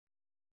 ♪ małti